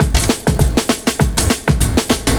100PERCS04.wav